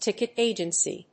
音節tícket àgency